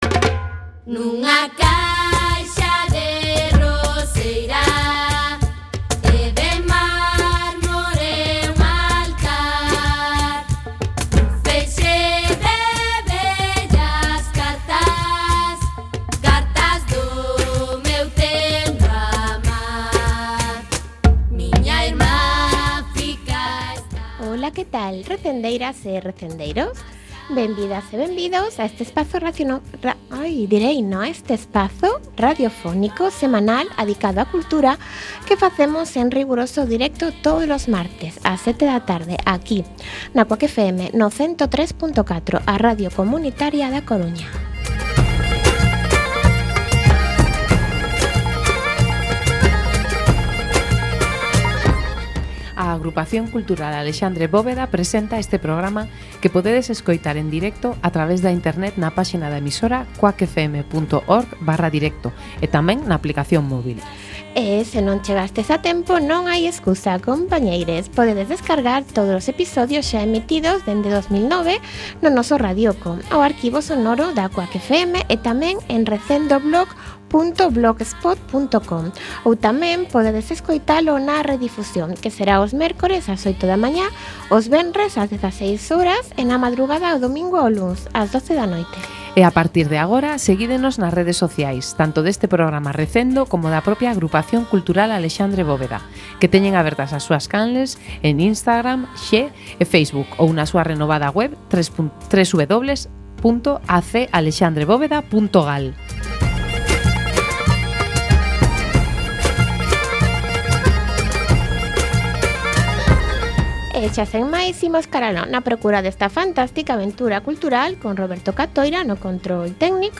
17x5 Entrevista Á Asociación Fibro Visibles